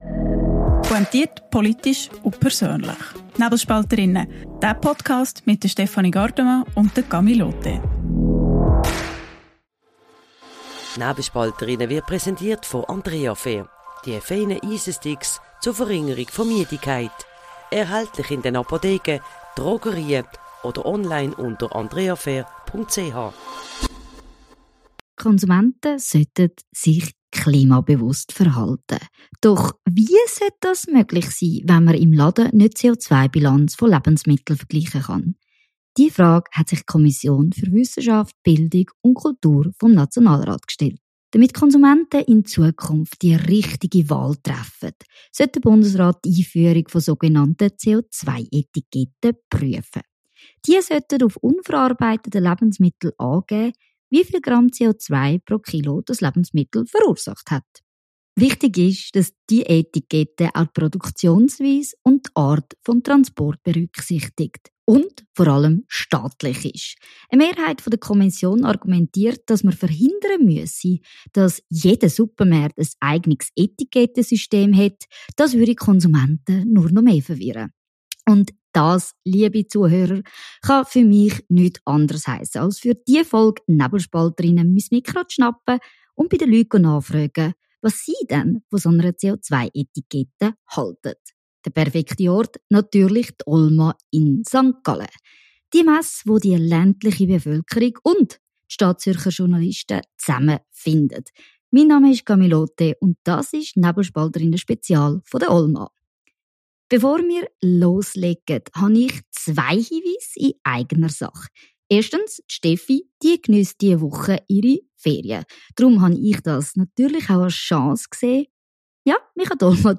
Spezial von der OLMA | Klimascore fürs Rüebli:– Kommt bald die CO2-Etikette?